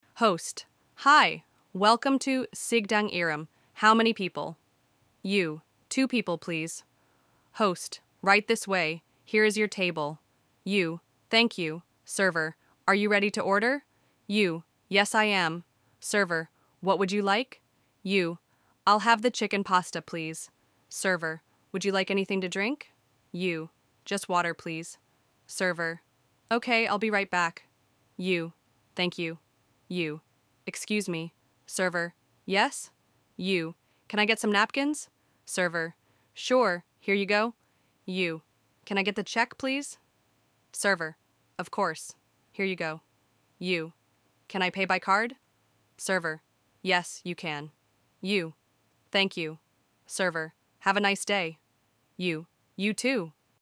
• ElevenLabs: 텍스트 음성 변환 (TTS)
• 각자 다른 역할(A, B)로 나뉜 대사를 ElevenLabs로 변환
• 전체 스크립트를 한 번에 읽는 방식이라 '진짜 대화' 같은 상호작용이 부족했어요
• ElevenLabs의 음성 품질은 놀라울 정도로 자연스러웠고, 따라 말하기 연습에는 꽤 유용했습니다